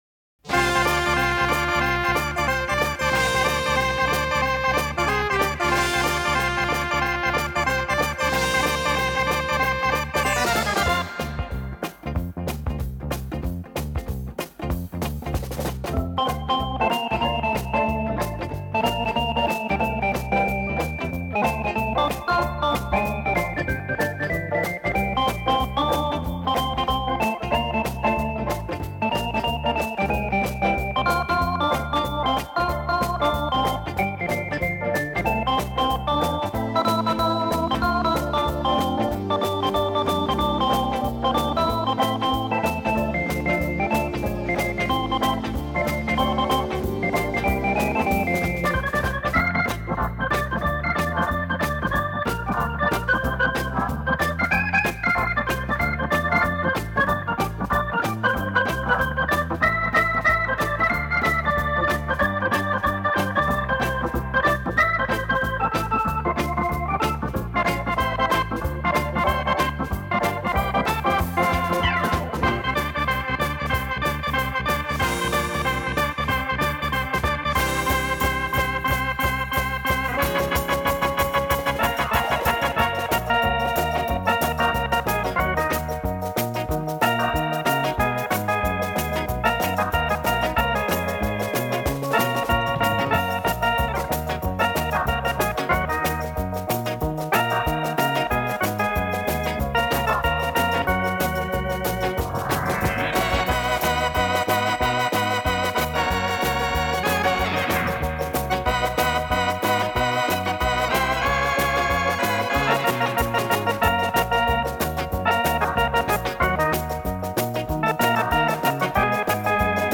加入了打击乐，并不是纯粹的电子琴，而是电子琴与小乐队
我们在欣赏中发现这个系列的唱片比较欢快，变奏和更换音色也比较频繁